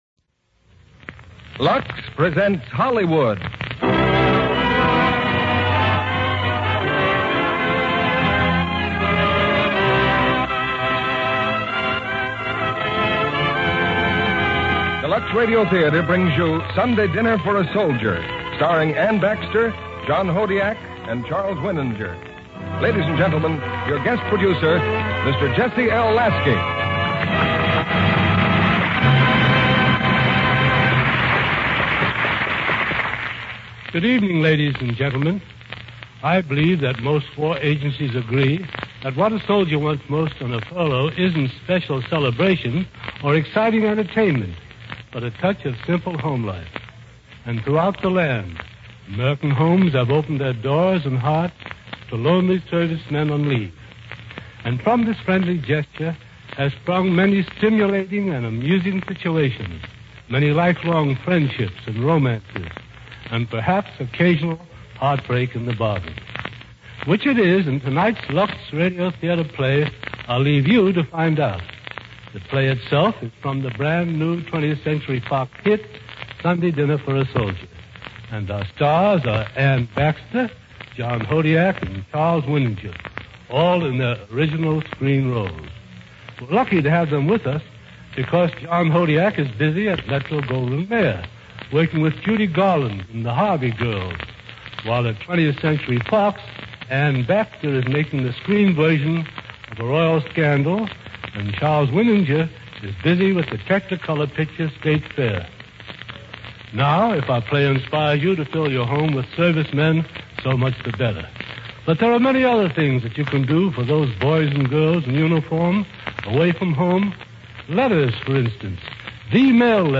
starring John Hodiak, Anne Baxter